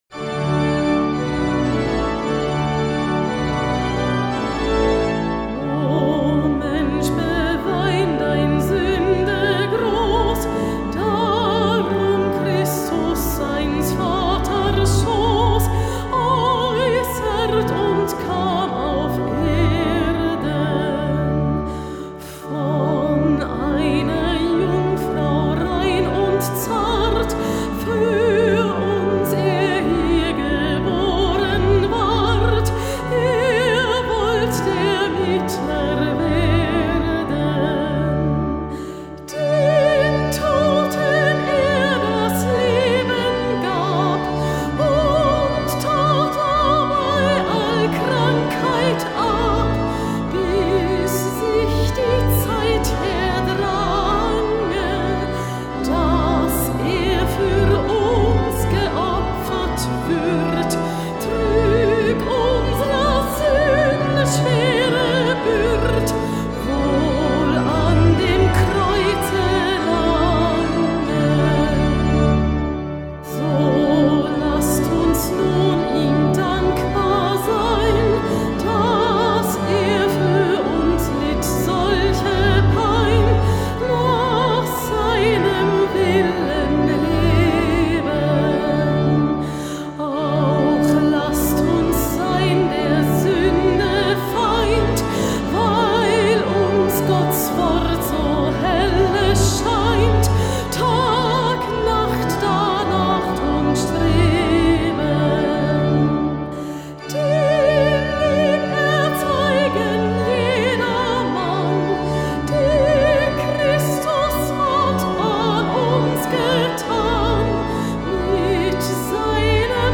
Wochenlied: